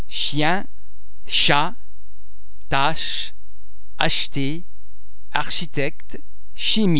Please be mindful of the fact that all the French sounds are produced with greater facial, throat and other phonatory muscle tension than any English sound.
The French [ch] and [sch] are normally pronounced [sh] as in the English words ash, shot, shy etc.
ch_chien.mp3